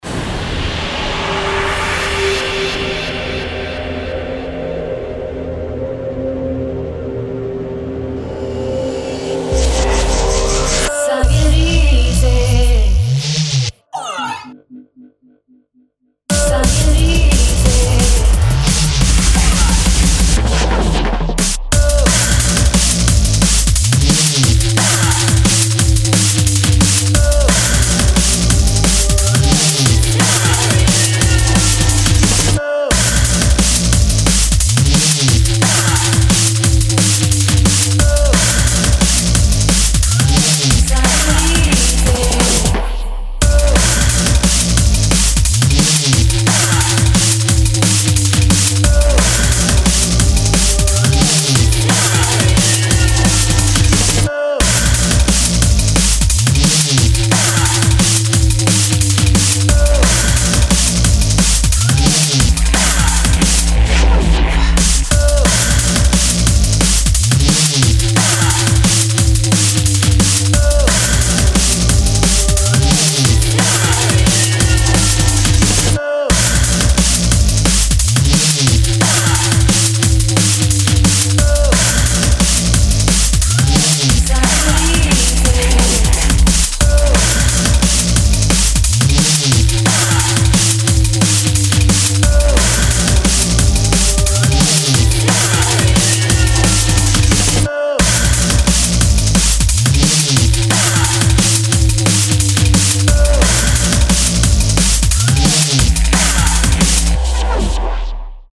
Genres Drum & Bass